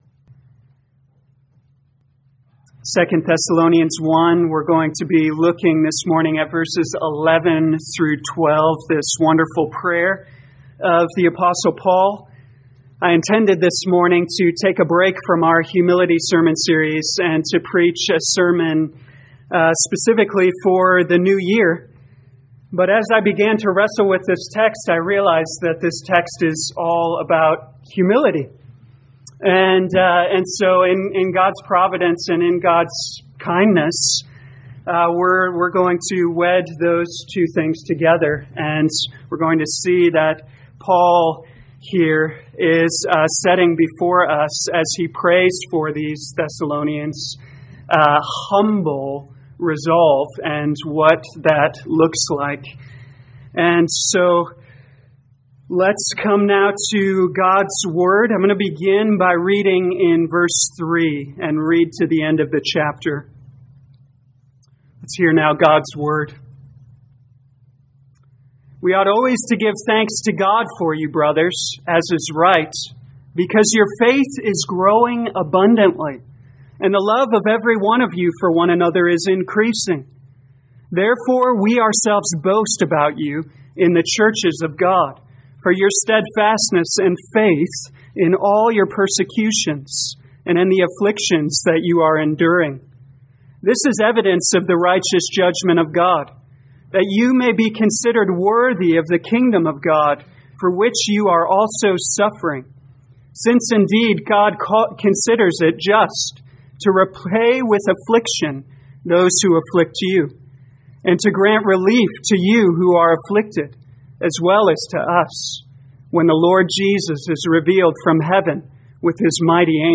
2020 2 Thessalonians Humility Morning Service Download